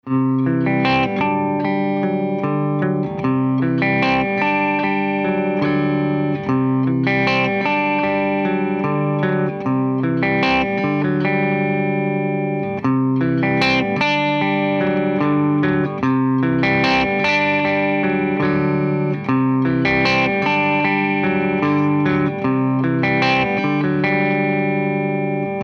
Fehler bei der Aufnahme
Dieses Geknister höre ich beim Mastern.. Das SM57 ist einen Finger breit vom Amp weg. Das Mic ist nicht zu nah an der Kalotte. Klingt ja ganz gut soweit, aber wenn ich stark komprimiere kommen diese Geräusche.